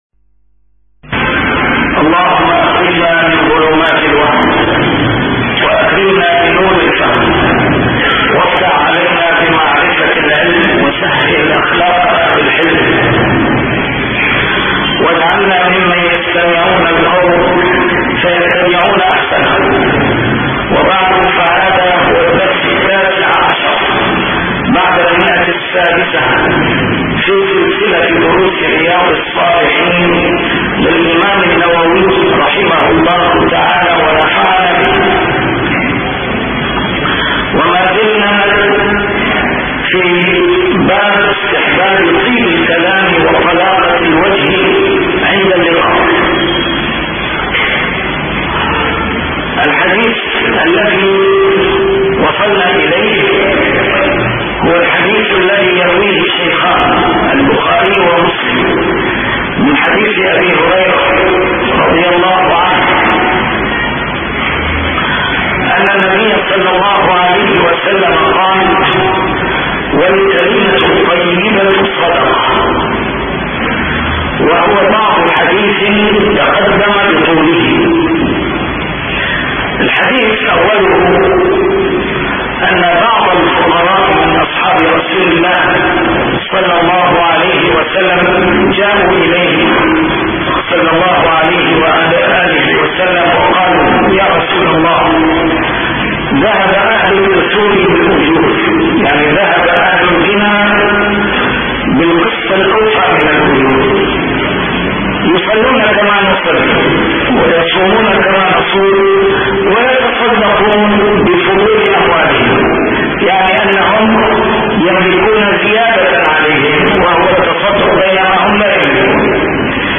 A MARTYR SCHOLAR: IMAM MUHAMMAD SAEED RAMADAN AL-BOUTI - الدروس العلمية - شرح كتاب رياض الصالحين - 619- شرح رياض الصالحين: طيب الكلام عند اللقاء